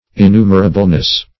-- In*nu"mer*a*ble*ness, n. -- In*nu"mer*a*bly, adv.